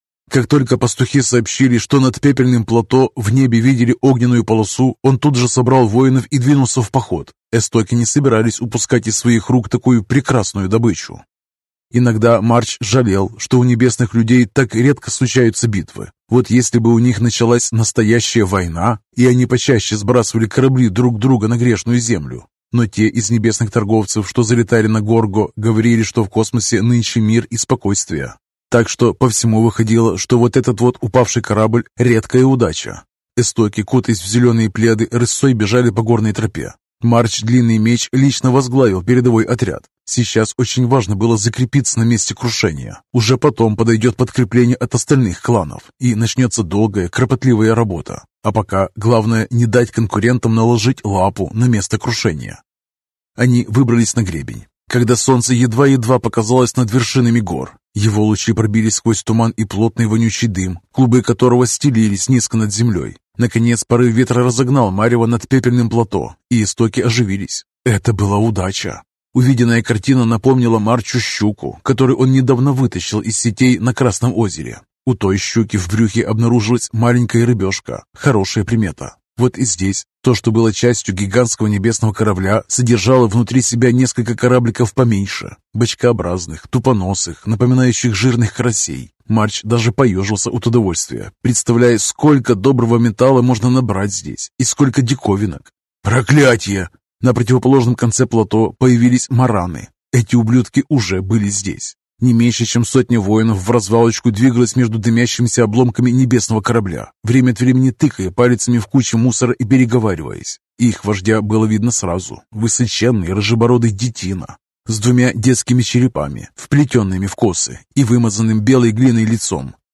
Аудиокнига Хорошо забытое | Библиотека аудиокниг